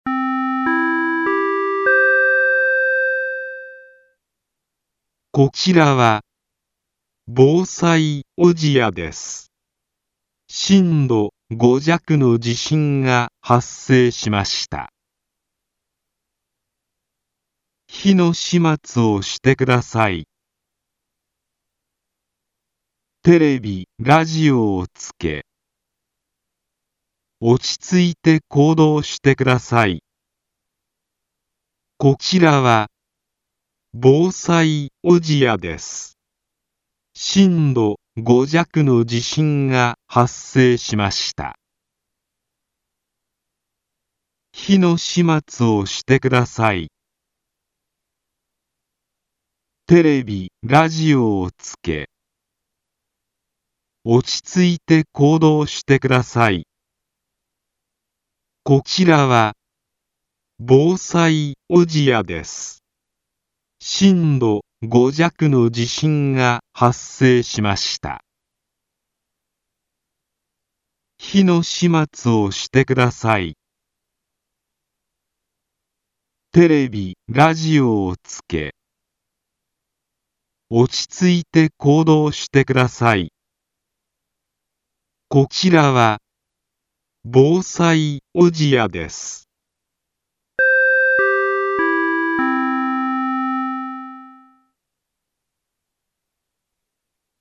全国瞬時警報システム（ジェイアラート）の音声サンプル